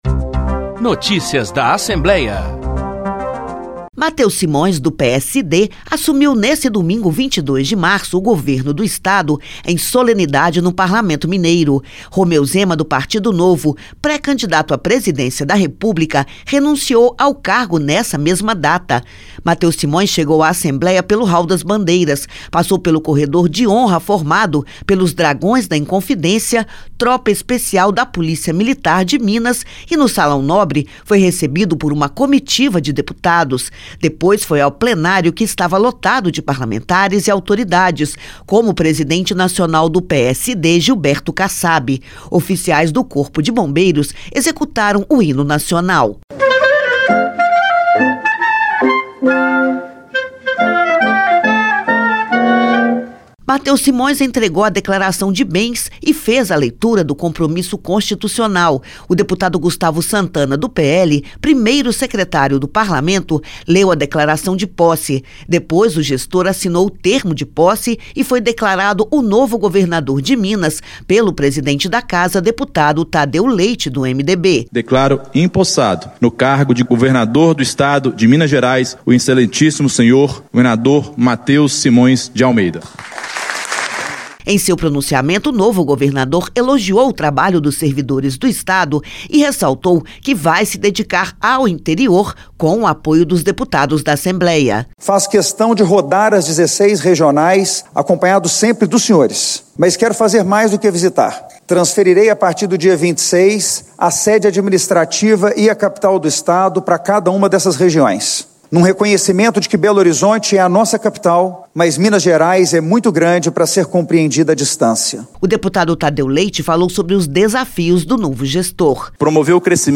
A posse foi neste domingo (22/3), em reunião solene no Plenário da ALMG.